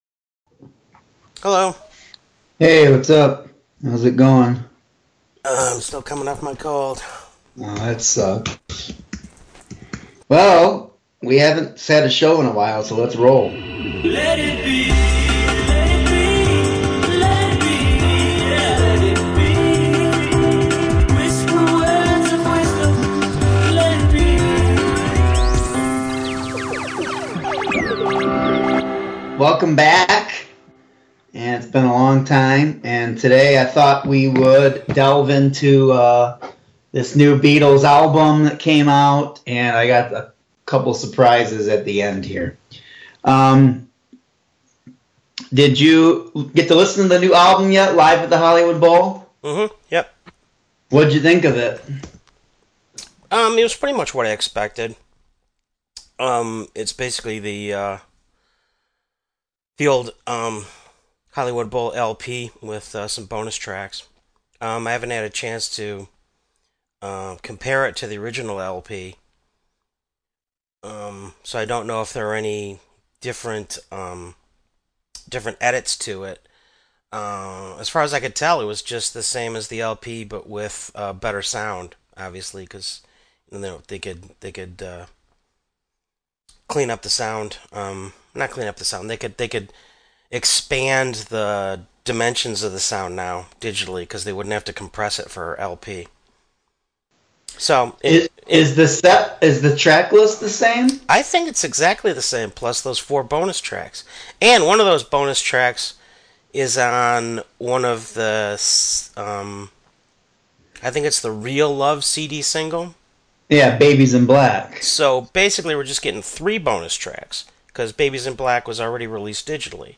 They were in fact taken from the August 7 show at the MeLife Stadium in East Rutherford NY (solely because the New York recording sounded better).